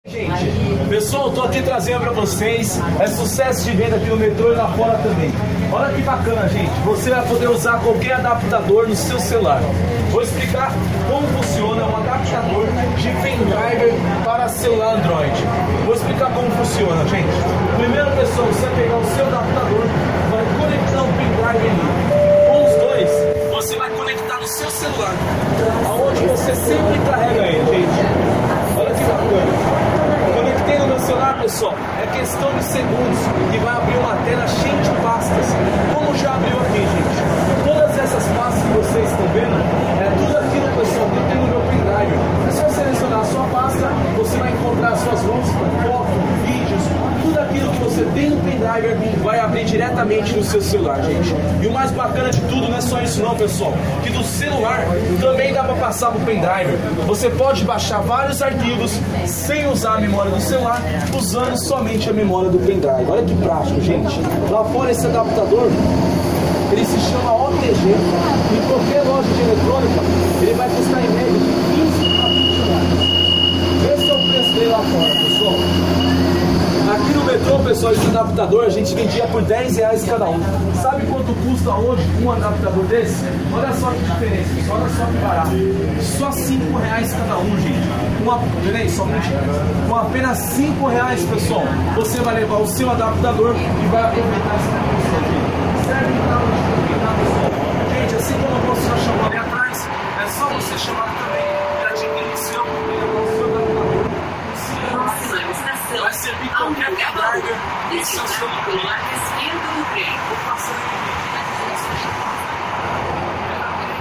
Para conferir, na manhã de hoje viajamos por todo o percurso da Linha Vermelha do Metrô, da Barra Funda até Corinthians-Itaquera. Tão logo foram fechadas as portas, ainda na Barra Funda, um ambulante se pôs a vociferar a plenos pulmões, anunciando a novidade do momento: Um cabo adaptador para “seu celular e o seu pen drive”. Usando a potente voz que Deus lhe deu, circula de um extremo a outro do vagão, anunciando as facilidades e benefícios que a novidade fornece, a preço extremamente convidativo.